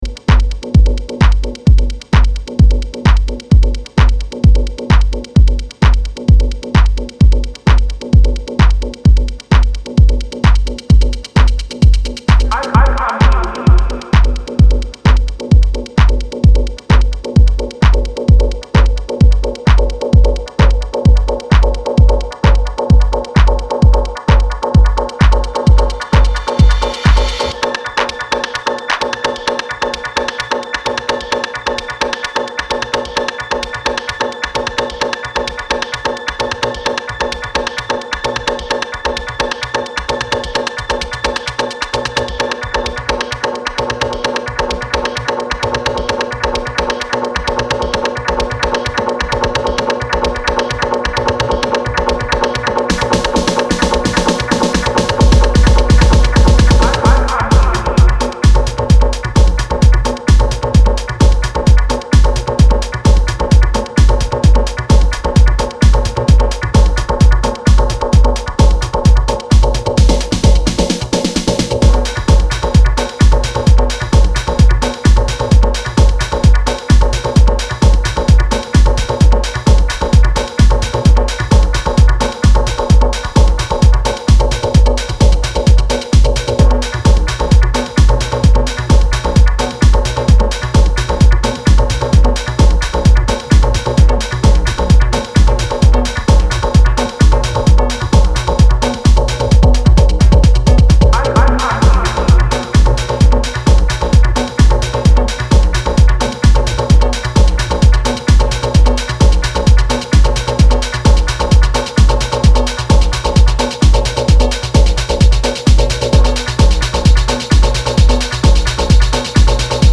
ミニマル・ハウス方面の端正な楽曲を提供しています。